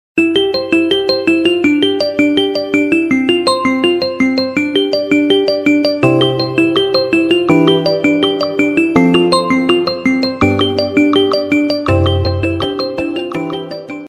• Качество: высокое